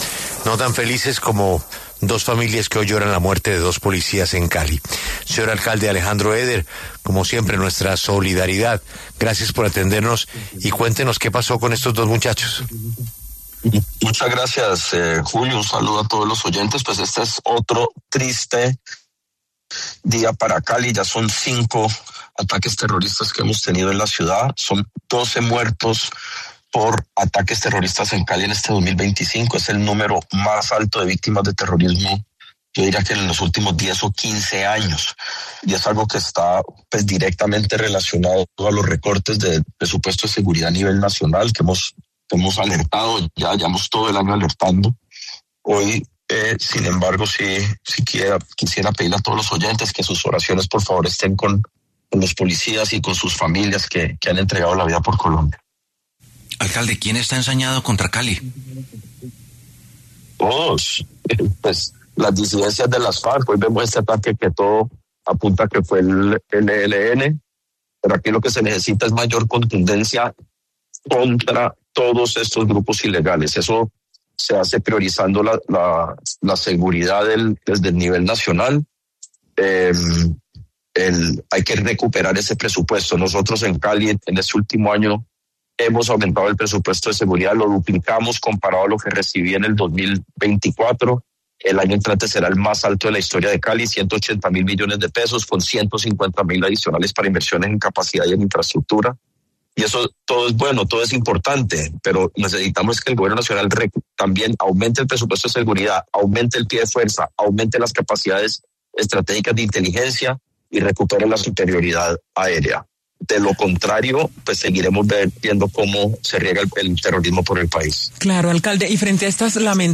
El alcalde de Cali, Alejandro Eder, pasó por los micrófonos de La W, con Julio Sánchez Cristo, para hablar acerca del atentado con explosivos en el suroriente de la capital del Valle del Cauca que dejó dos policías muertos.